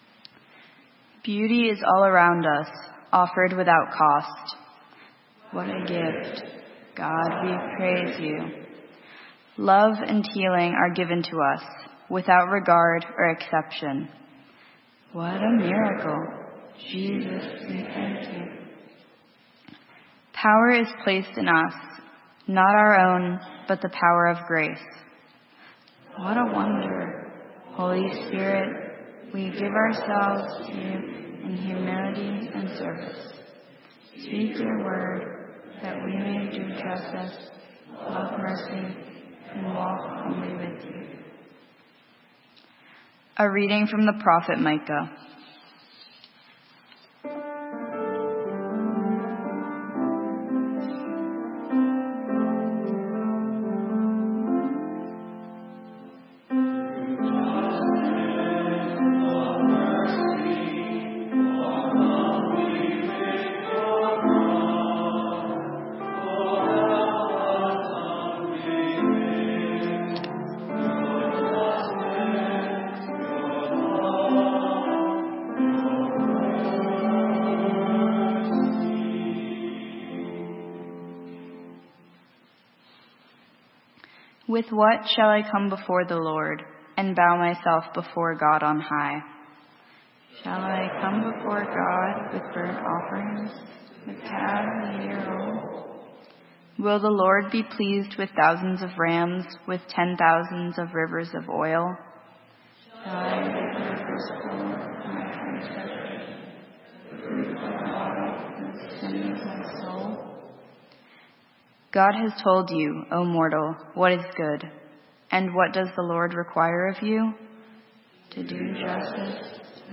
Sermon:Beatitudes - St. Matthews United Methodist Church